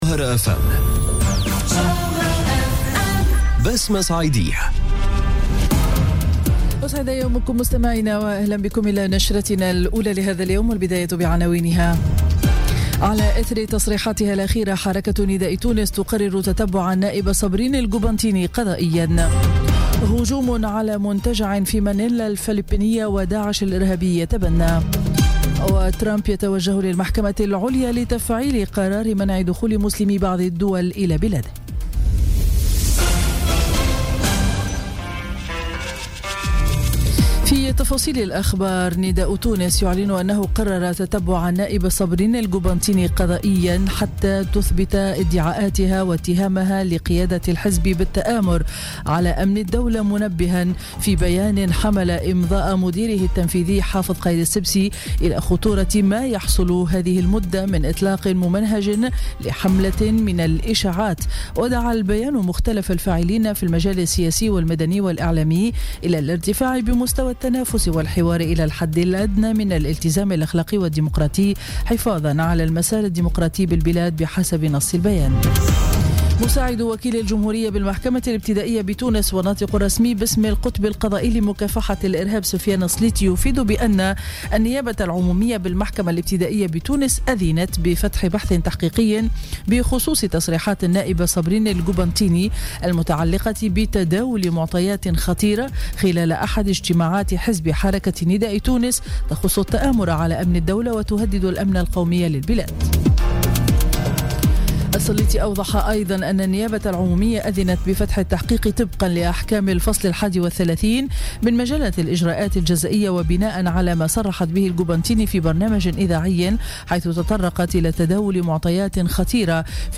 نشرة أخبار السابعة صباحا ليوم الجمعة 2 جوان 2017